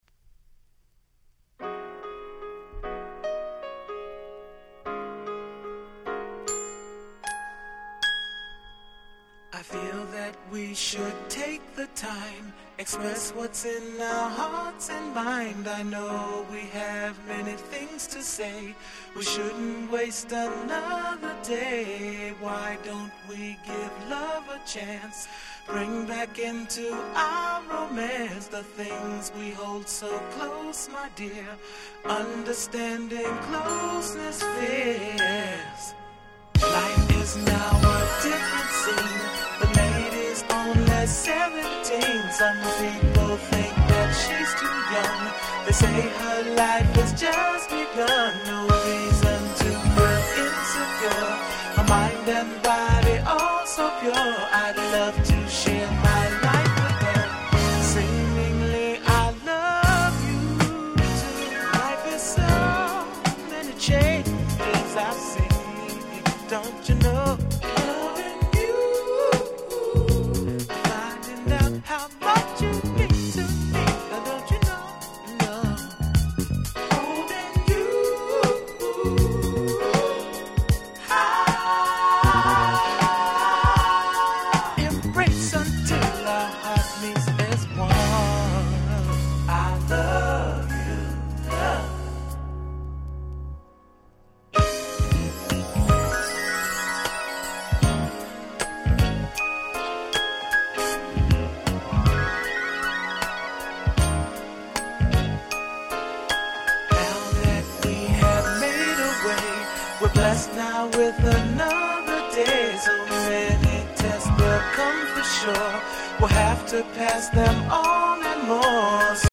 82' Rare Groove/Soul Super Classics !!